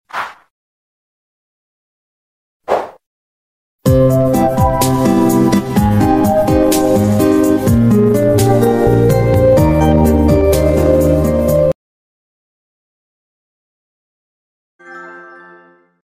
Sony PlayStation Notification Animation Evolution